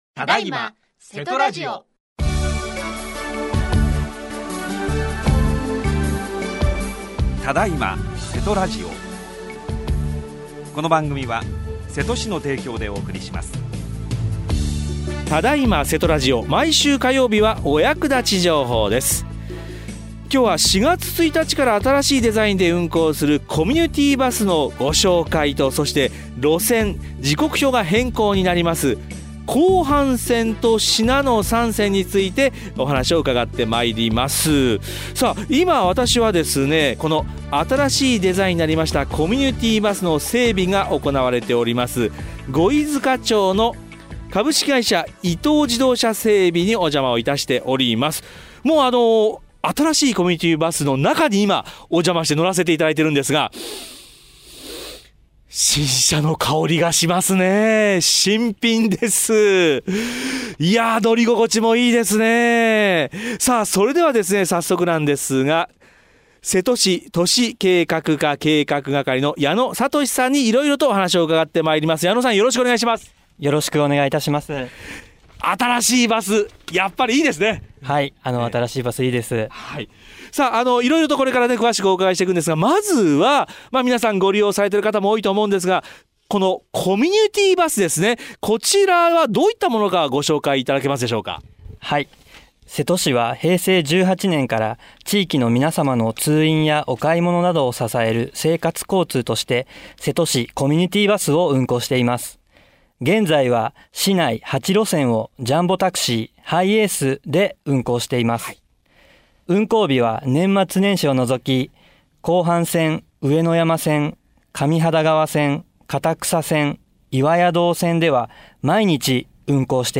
今日は現場リポート です。